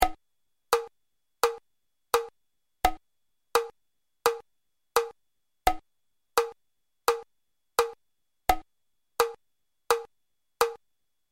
Für die Hörbeispiele habe ich einen einfachen 4/4 Takt gewählt.
andante
gehend
BPM: 85